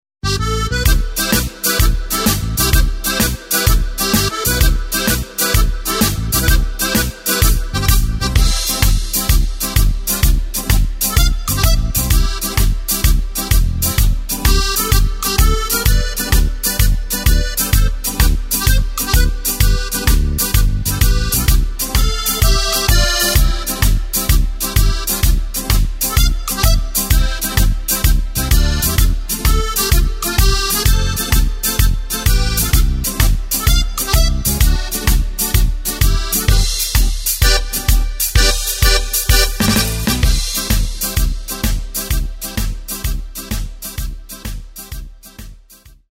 Takt:          2/4
Tempo:         128.00
Tonart:            C
Weihnachts Schlager-Polka aus dem Jahr 2004!
Playback mp3 Demo